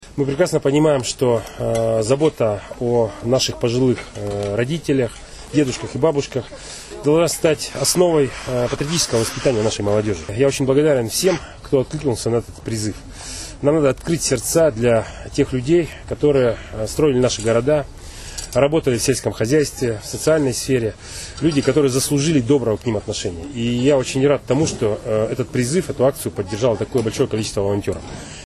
Олег Кувшинников о старте нового социального проекта